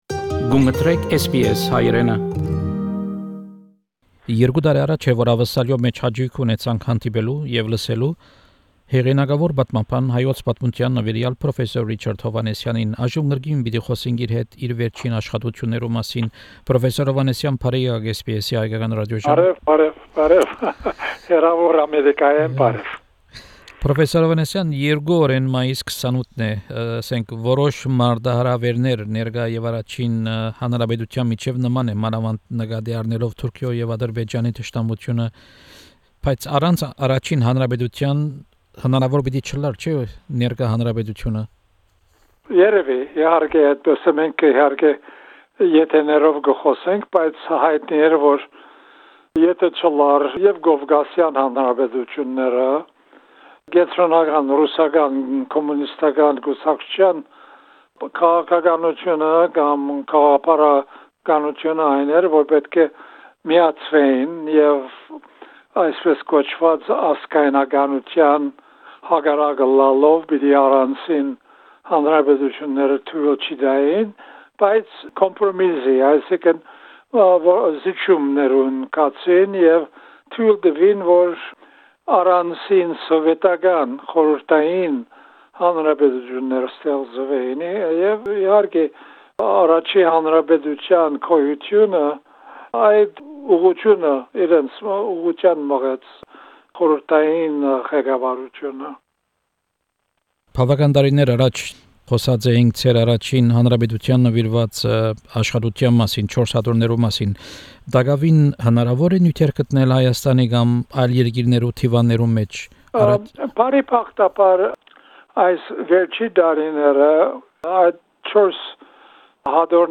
Interview with Professor Richard Hovannisian about the 102nd anniversary of the First Republic, archival material relating to his research, similarities between the situation in 1918 and 2020, Njteh’s legacy and his latest research.